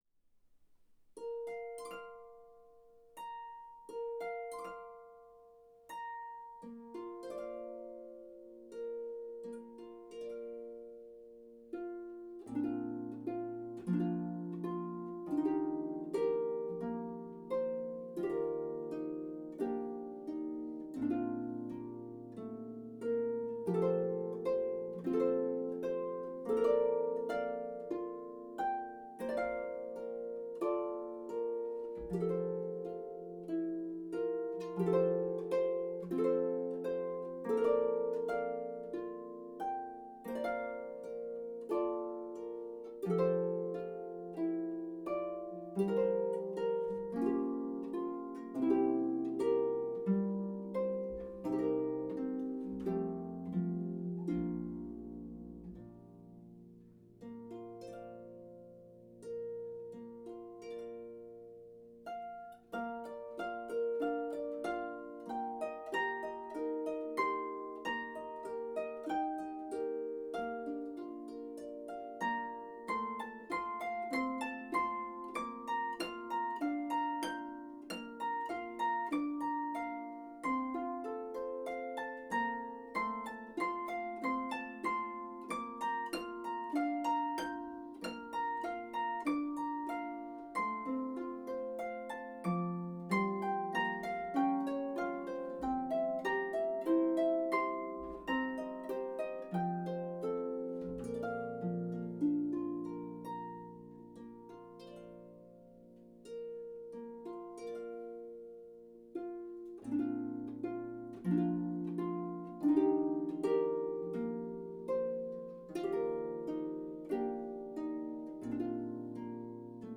traditional folk song
is for solo pedal harp